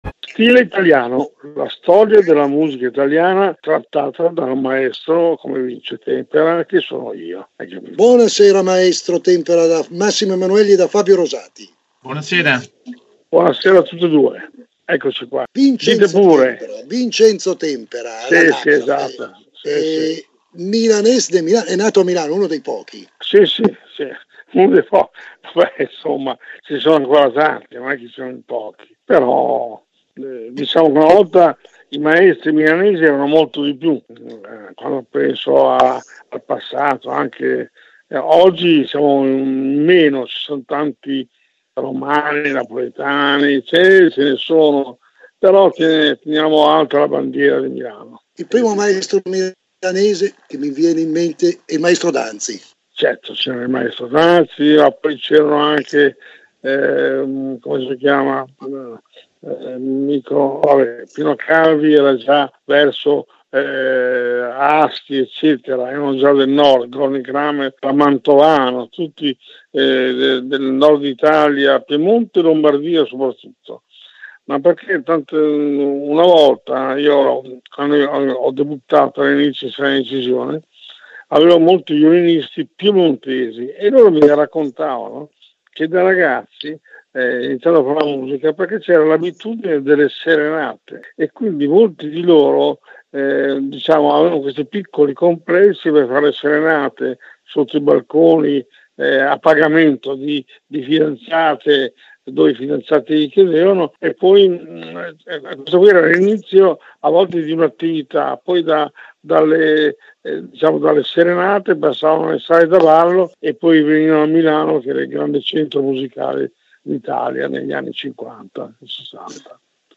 Il podcast. Vince Tempera intervistato
vince-tempera-solo-parlato.mp3